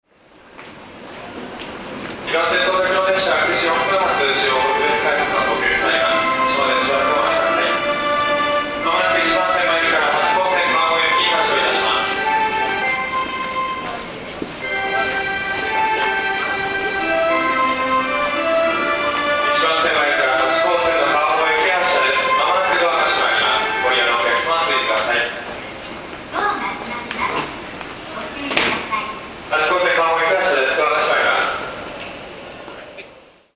全ホーム旋律は同じですがアレンジが異なります。